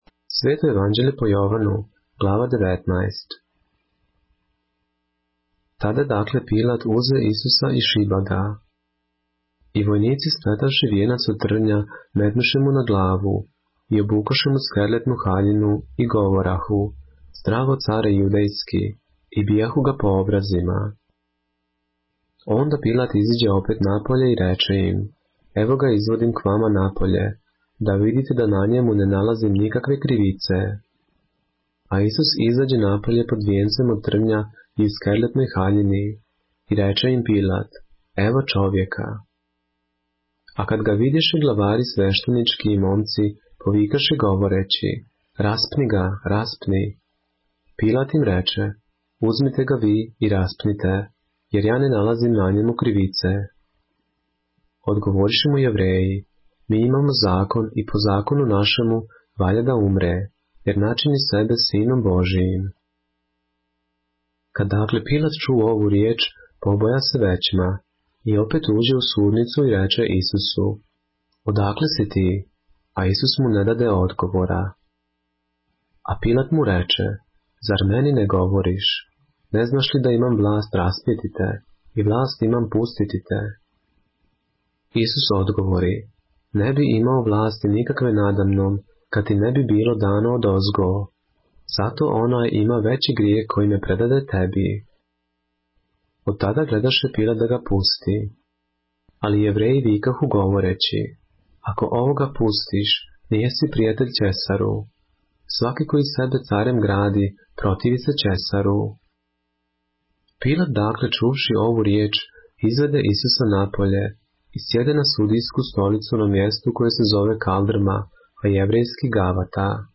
поглавље српске Библије - са аудио нарације - John, chapter 19 of the Holy Bible in the Serbian language